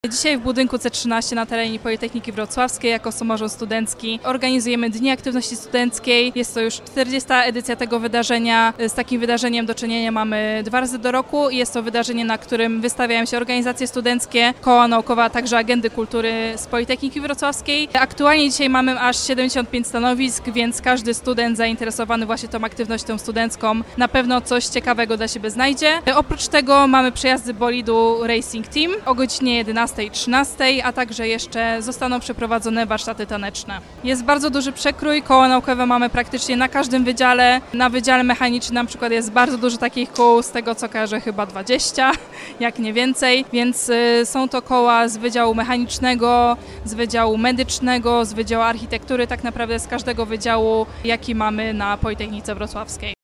O inicjatywie mówi przedstawicielka Samorządu Studenckiego.